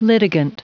Prononciation du mot litigant en anglais (fichier audio)
Prononciation du mot : litigant